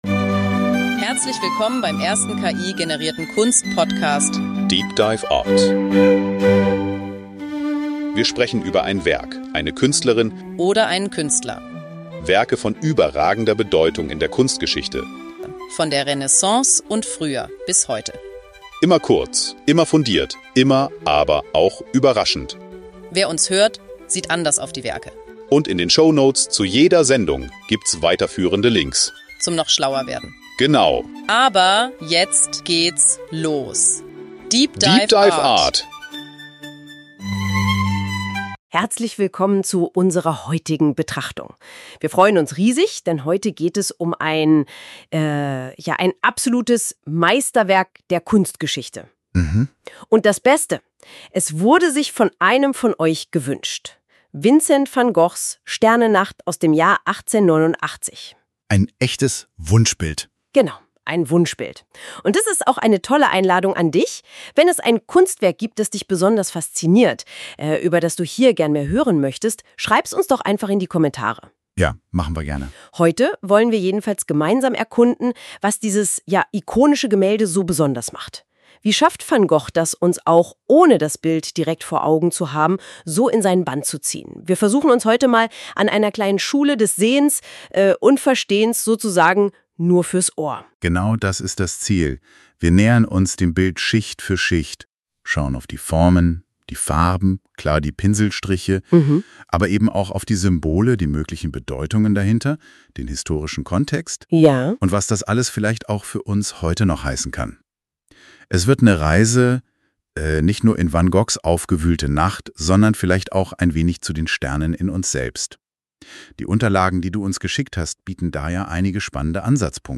Wir untersuchen das Werk aus mehreren Perspektiven; beginnend mit dem ersten emotionalen Eindruck, der das Bild als intensiv und hypnotisch beschreibt. Anschließend folgt eine formale Analyse, welche die dynamische Komposition, den expressiven Einsatz von Licht und Schatten sowie den charakteristisch kurvigen Pinselduktus hervorhebt. DEEP DIVE ART ist der erste voll-ki-generierte Kunst-Podcast.
Die beiden Hosts, die Musik, das Episodenfoto, alles.